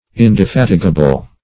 Indefatigable \In`de*fat"i*ga*ble\